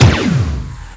plasmarifle_fire03.wav